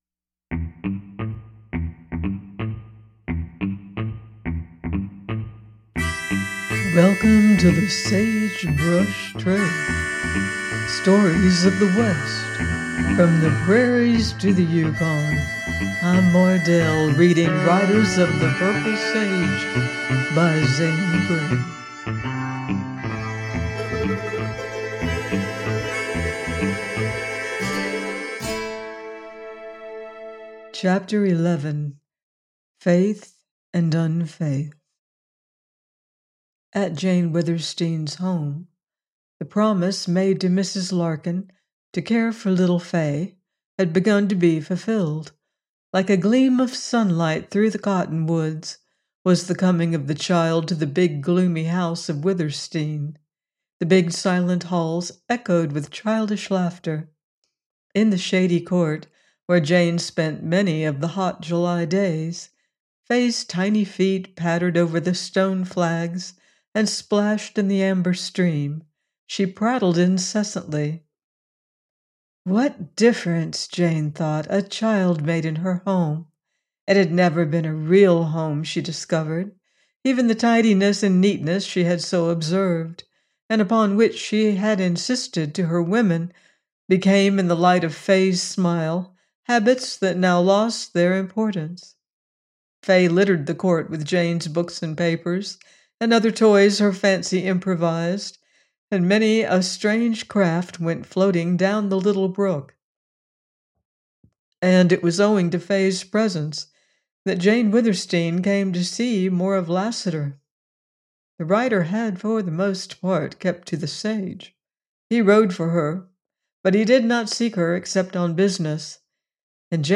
Riders Of The Purple Sage – Ch. 11: by Zane Grey - audiobook